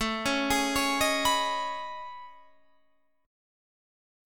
AM#11 chord